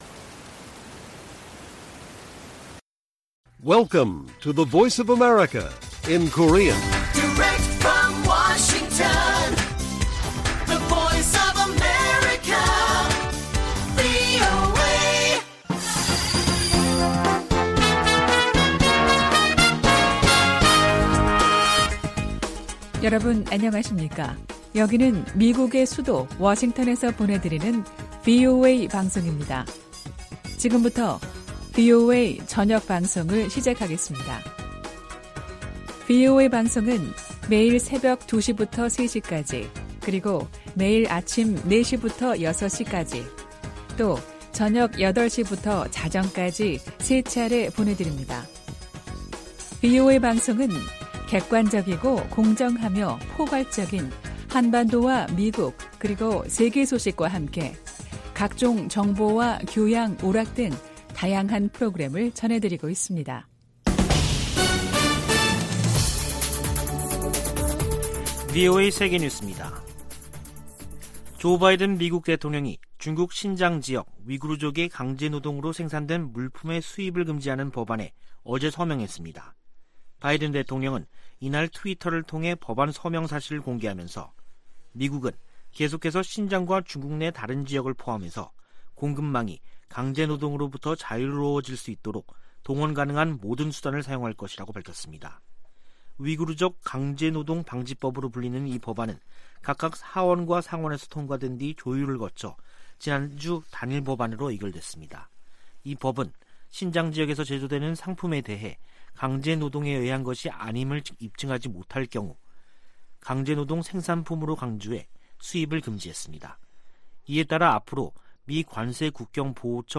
VOA 한국어 간판 뉴스 프로그램 '뉴스 투데이', 2021년 12월 24일 1부 방송입니다. 미국 내 구호단체들은 올 한 해가 대북 지원 사업에 가장 도전적인 해였다고 밝혔습니다. 미 델라웨어 소재 'TD 뱅크그룹'이 대북 제재 위반 혐의로 11만5천 달러 벌금에 합의했다고 미 재무부가 밝혔습니다. 한국과 중국이 4년 반 만에 가진 외교차관 전략대화에서 종전선언 등에 협력 방안을 논의했습니다.